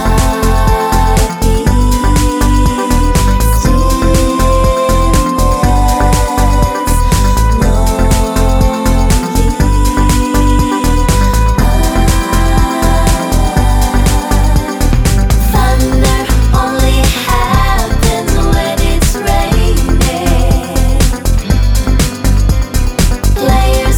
No Backing Vocals Irish 4:05 Buy £1.50